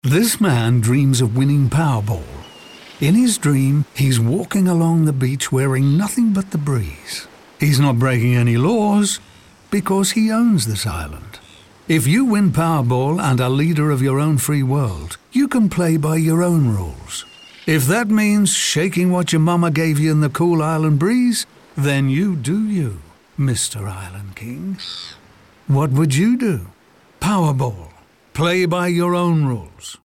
In radio, we brought to life one players dream to become King of their own private island, not told in a boring way, but in an entertaining and humorous way that’s true to the brand.
Leader_30_Radio 2021-Powerball-Dreams-Leader-of-Your-Own-Free-World-Sd1YaGZmnPT3dip4